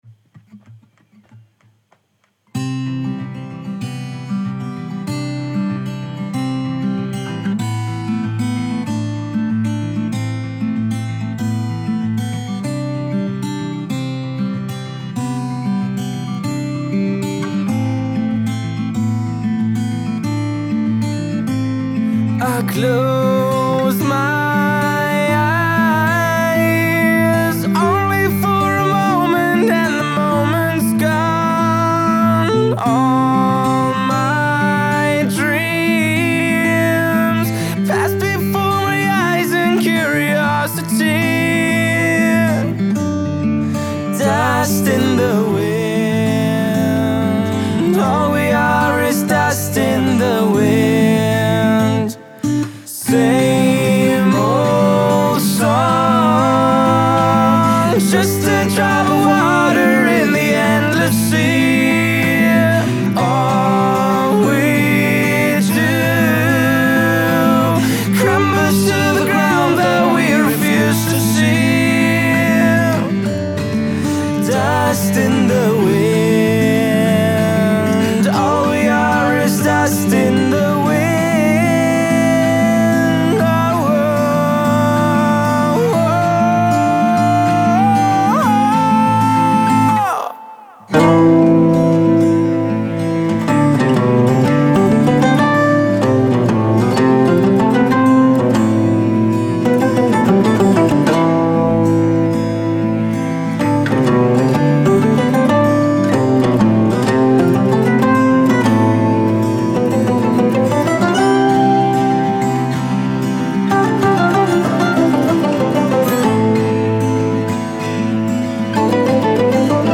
handgemachten Acoustic-Rock tanzbare Lagerfeuerstimmung
• Coverband